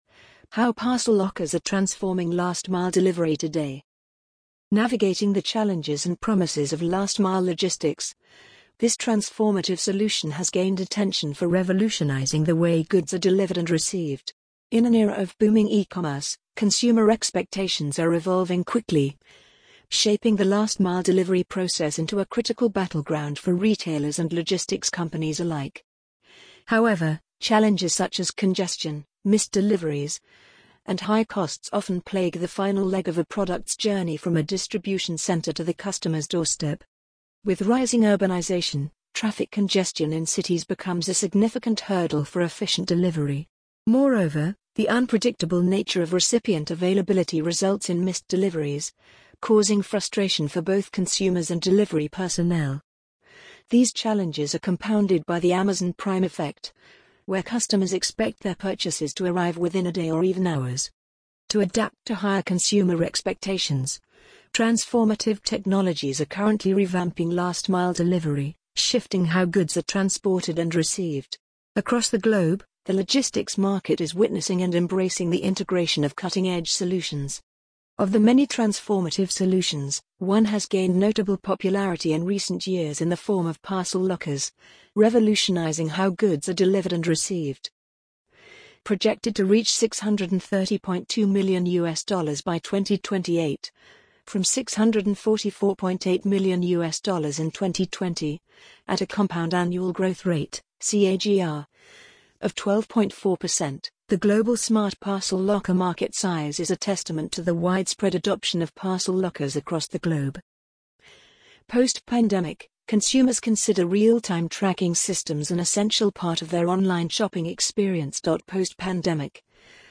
amazon_polly_44824.mp3